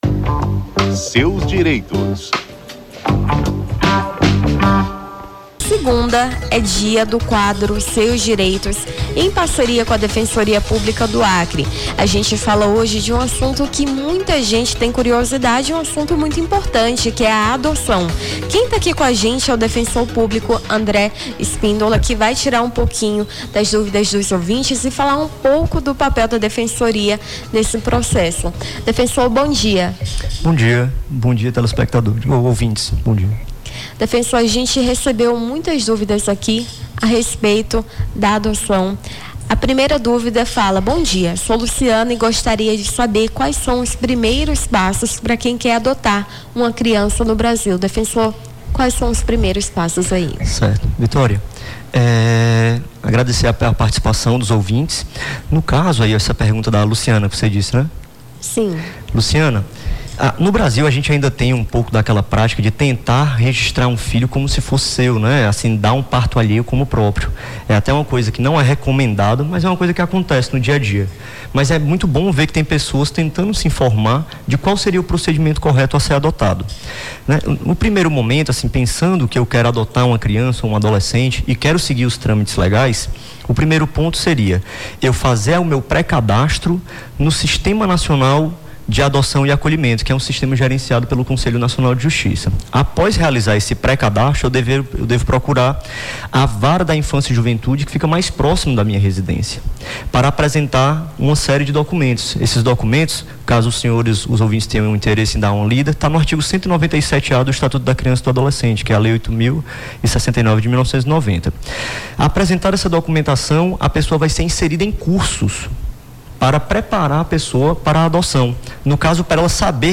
Seus Direitos: defensor público tira dúvidas sobre adoção
No Jornal da Manhã desta segunda-feira (26)